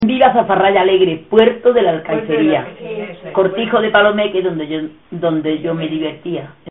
Materia / geográfico / evento: Canciones populares Icono con lupa
Secciones - Biblioteca de Voces - Cultura oral